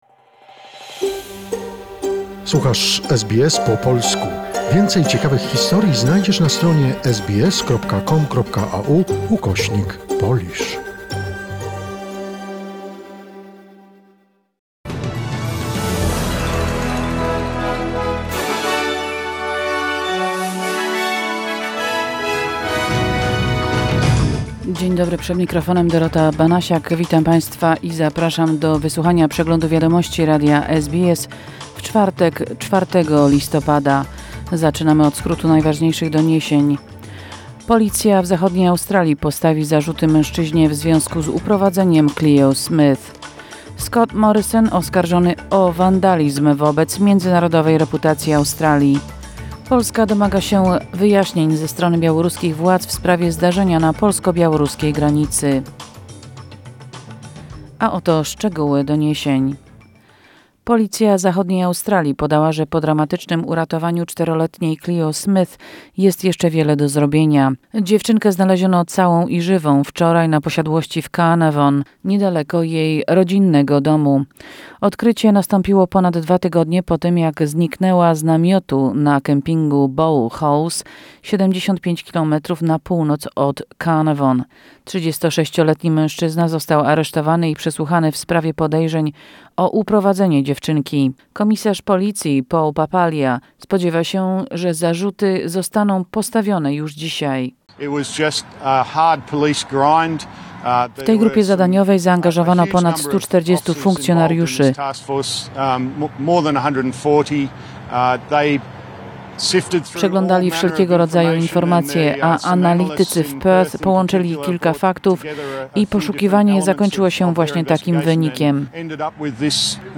SBS POLISH SBS News Flash in Polish, 4 November 2021